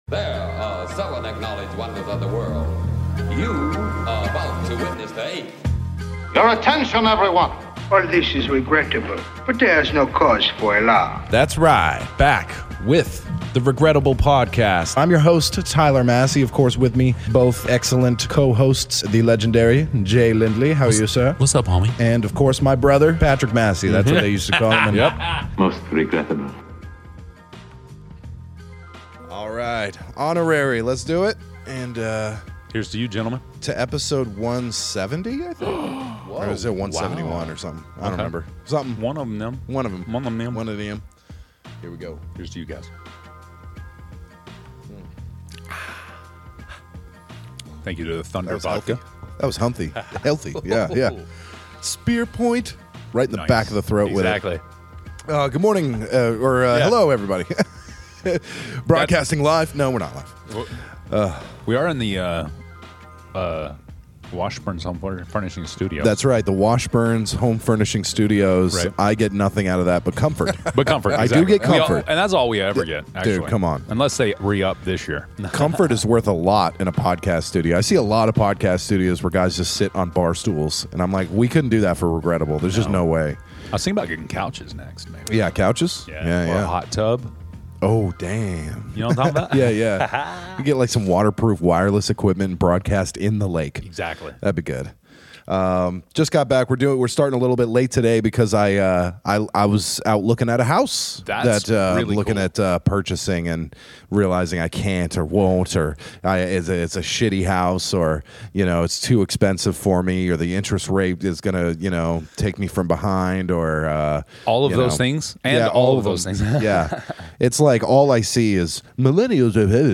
Disclaimer: Strong language, Sensitive subjects.